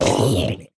Minecraft Version Minecraft Version 25w18a Latest Release | Latest Snapshot 25w18a / assets / minecraft / sounds / mob / zombie_villager / death.ogg Compare With Compare With Latest Release | Latest Snapshot
death.ogg